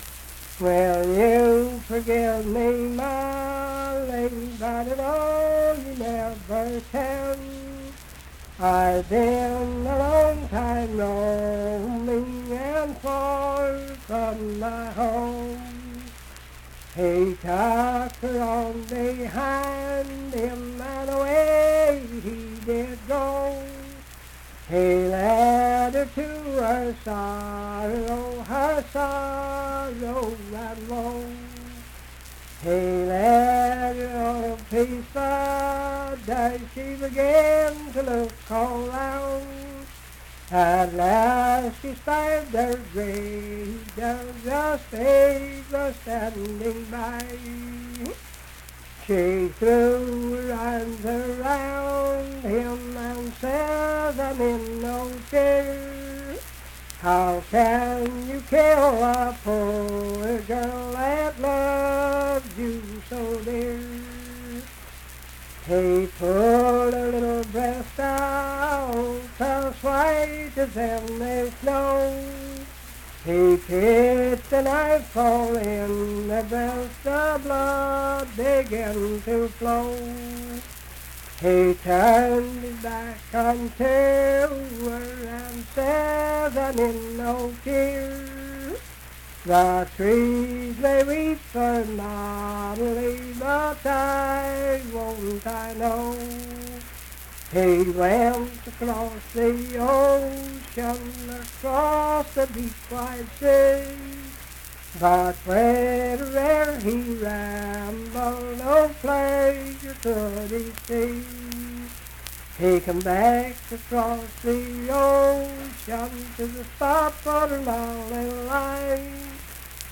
Unaccompanied vocal music
Verse-refrain 8(4).
Performed in Ivydale, Clay County, WV.
Voice (sung)